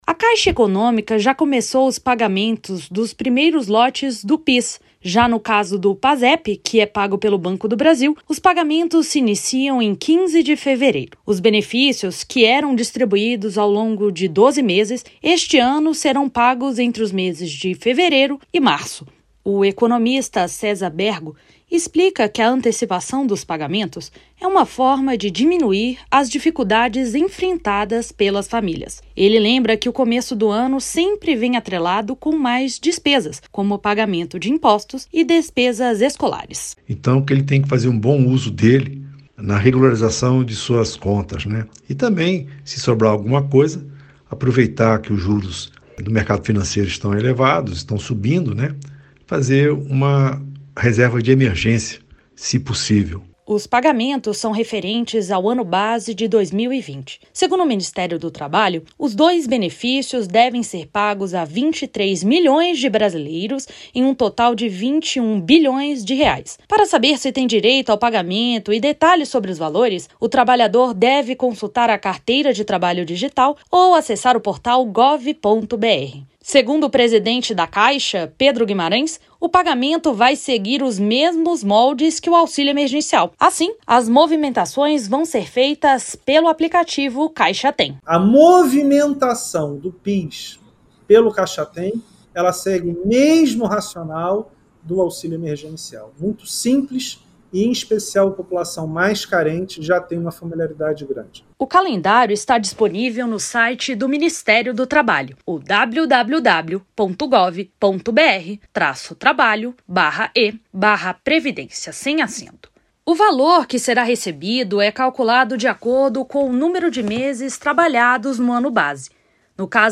MATÉRIA EM ÁUDIO